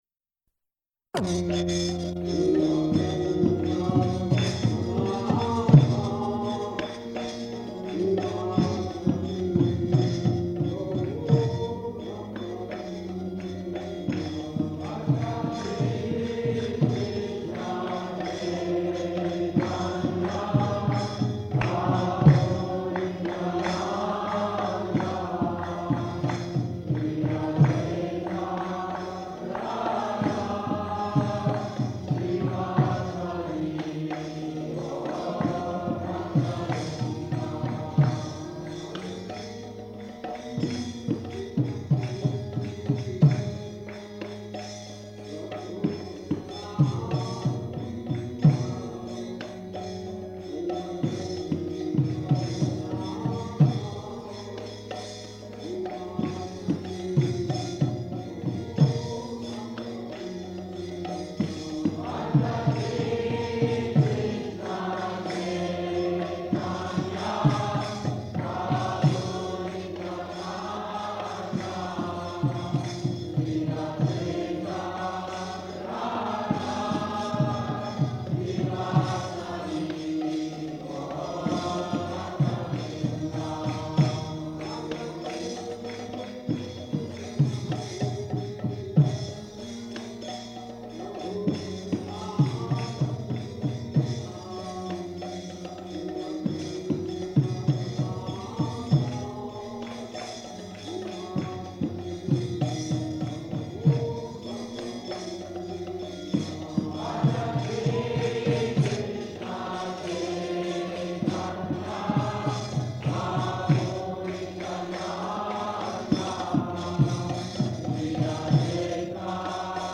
Location: Los Angeles
690120SB-LOS_ANGELES.mp3 [ kīrtana ] [Prabhupāda plays gong]